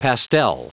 Prononciation du mot pastel en anglais (fichier audio)
Prononciation du mot : pastel